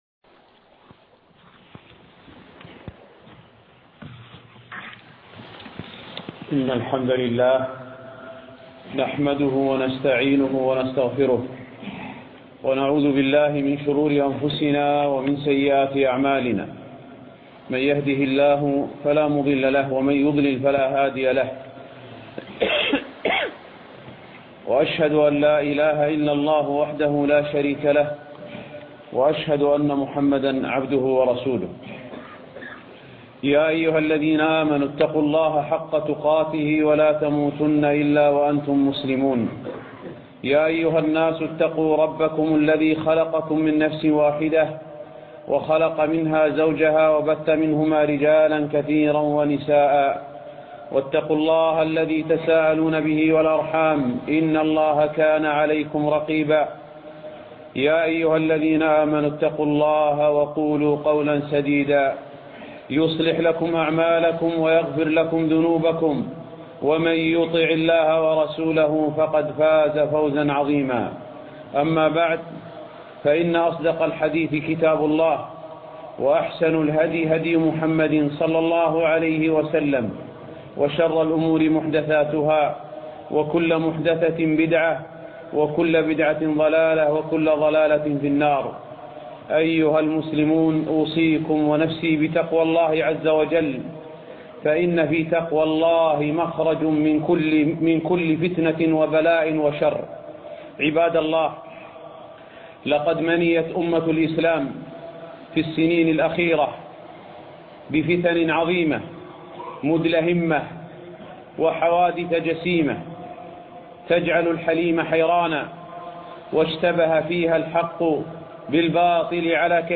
خطبة بتاريخ 6 2 2015